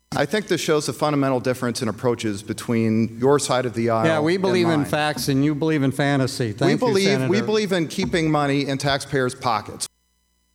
There was a sharp exchange when Democratic Senator Herman Quirmbach asked Republican Senator Charles Schneider where the G-O-P planned to cut the state budget to make up for the tax reductions.